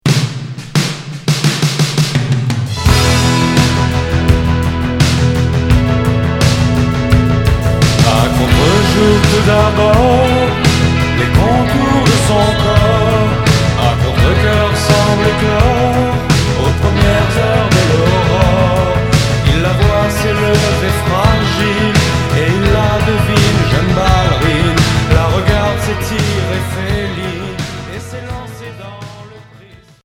Rock cold wave